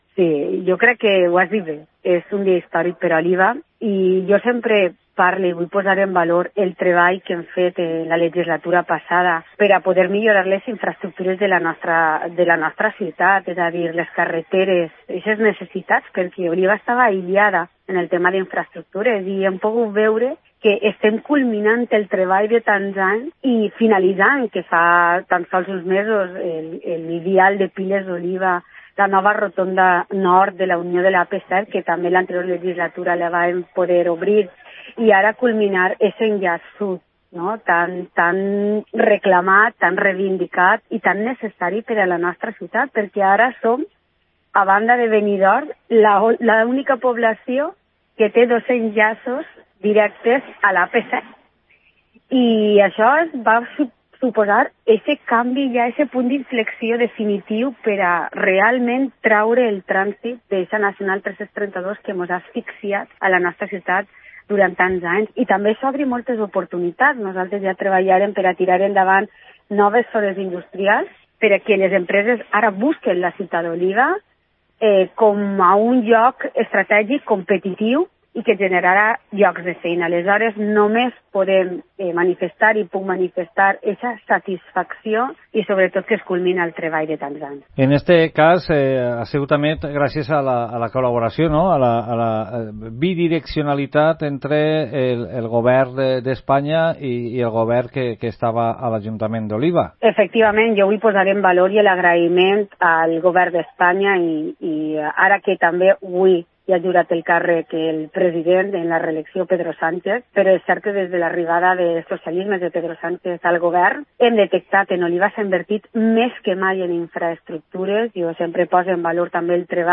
Ana Morell, ex vicealcaldesa de Oliva valora para COPE la obra que se ha inaugurado hoy